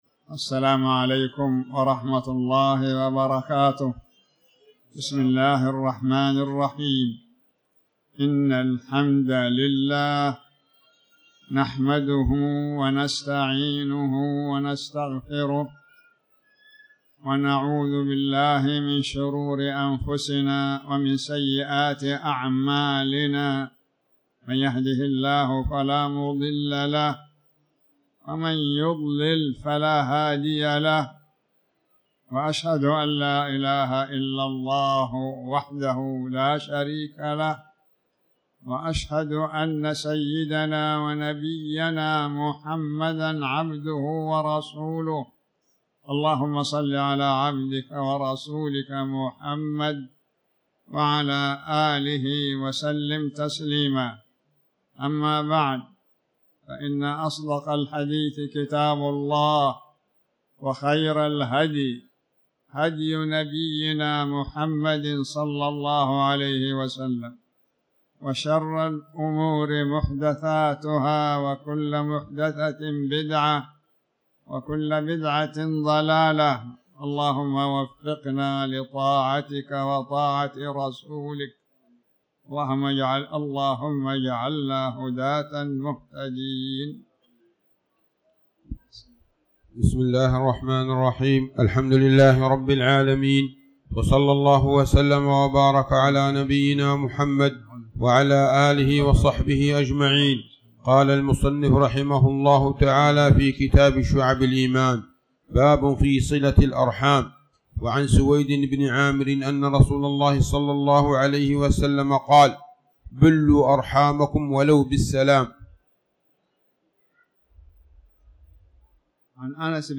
تاريخ النشر ١٢ جمادى الآخرة ١٤٤٠ هـ المكان: المسجد الحرام الشيخ